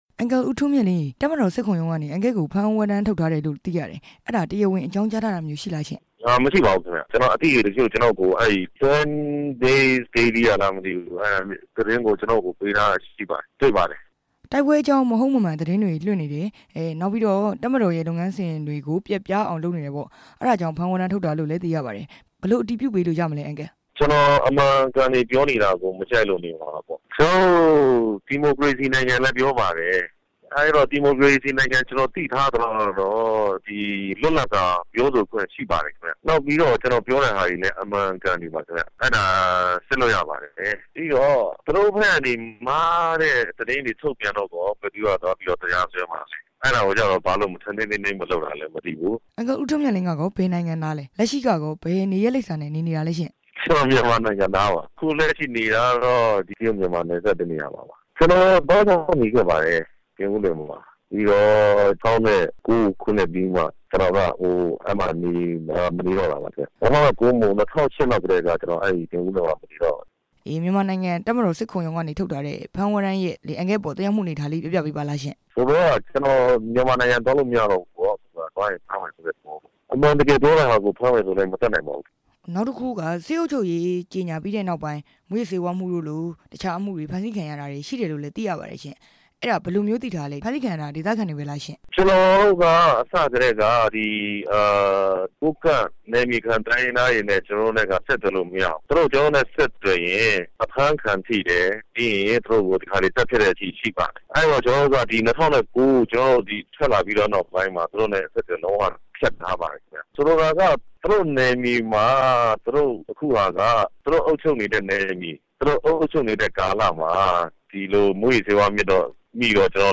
မေးမြန်ချက်